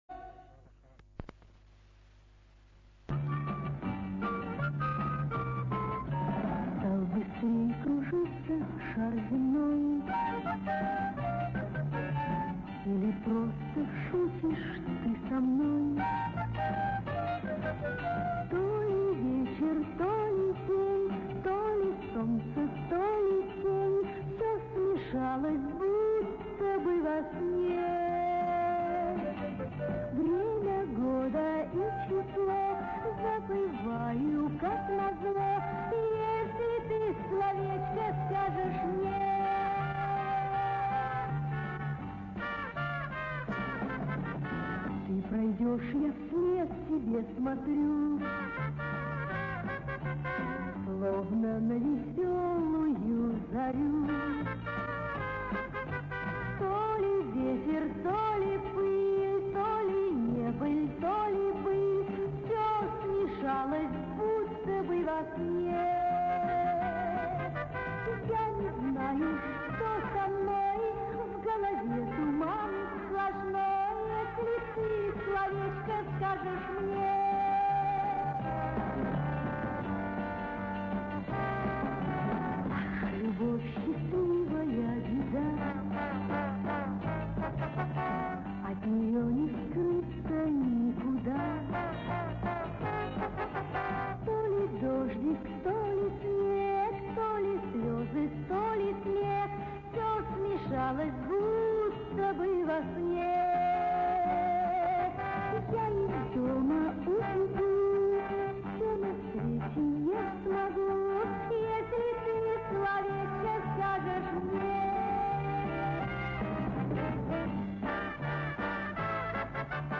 Оригинальная запись с радио 1970 год